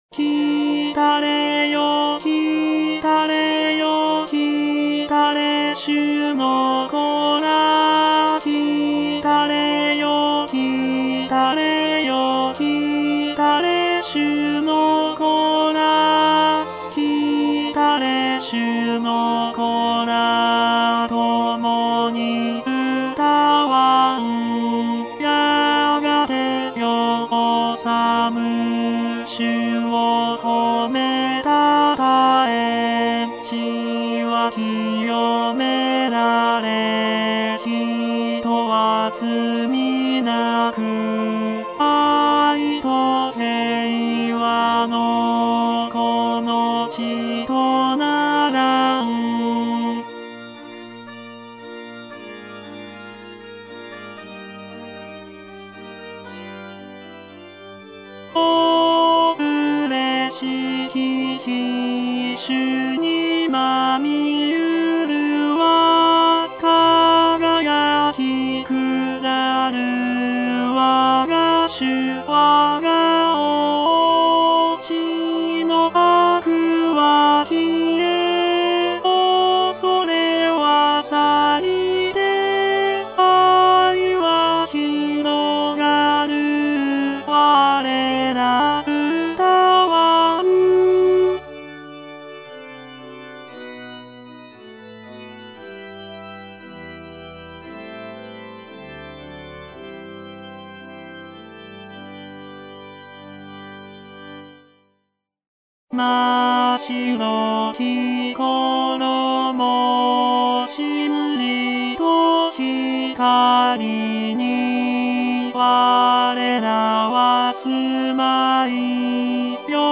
＊テンポはすべて一定にしてあるので音取りのみに使用し、実際に歌うときは楽譜の指示、指揮者を見る。
テノール（歌詞付き）